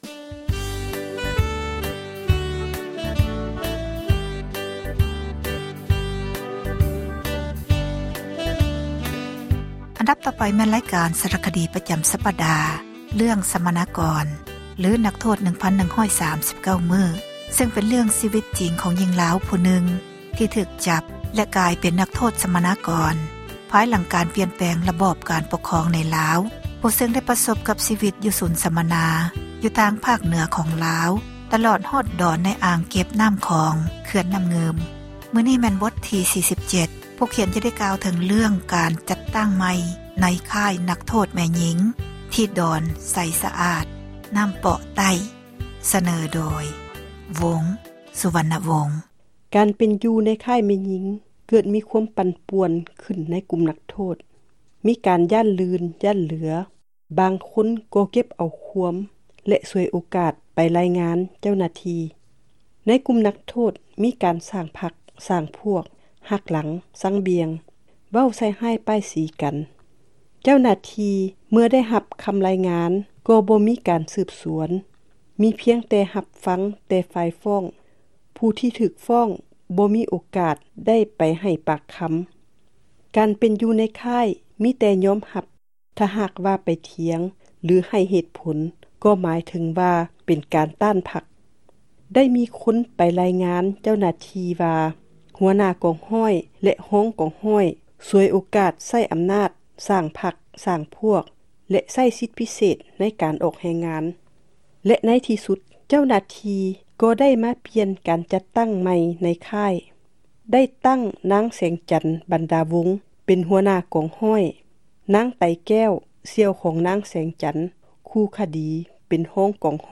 ສາຣະຄະດີ ເຣື້ອງ ສັມມະນາກອນ ຫຼື ນັກໂທດ 1139 ມື້,  ຊຶ່ງ ເປັນເຣື້ອງ ຊີວິດຈິງ ຂອງ ຍິງລາວ ທີ່ຖືກຈັບ ແລະ ກາຍເປັນ ນັກໂທດ ສັມມະນາກອນ ພາຍຫລັງ ປ່ຽນຣະບອບ ການປົກຄອງ ໃນລາວ ນັກຮຽນ ຜູ້ນີ້ ໄດ້ໃຊ້ ຊີວິດ ໃນສູນ ສັມມະນາ ຢູ່ທາງ ພາກເໜືອ ຂອງ ລາວ ຕລອດຮອດ ດອນໃນອ່າງ ເກັບນໍ້າ ຂອງ ເຂື່ອນນໍ້າງຶ່ມ.